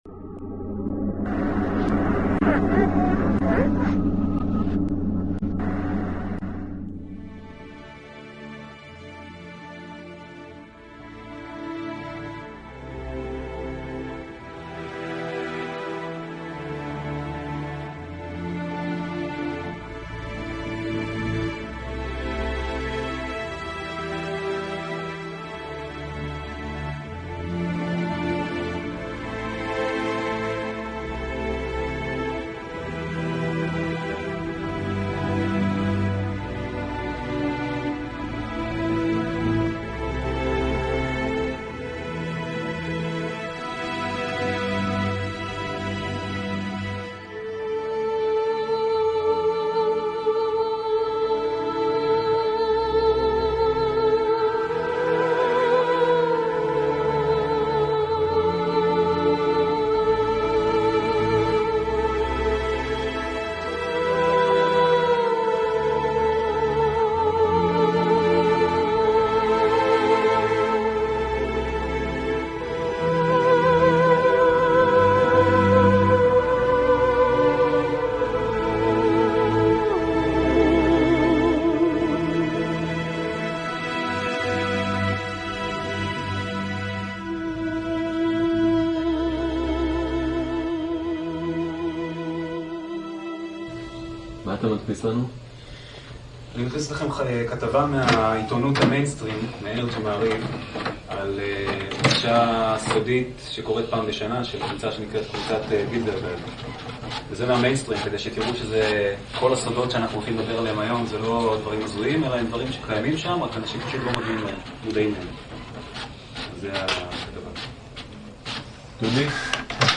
מאחורי הקלעים: ראיון לא מצונזר לערוץ 10 על קונספירציות